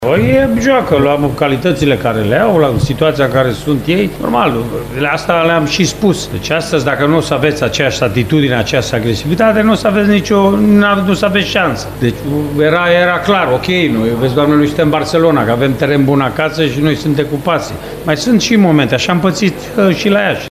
La final, Mircea Rednic a declarat că diferența s-a făcut la atitudine.
14-apr-8.30-Rednic-diferenta-s-a-facut-la-atitudine.mp3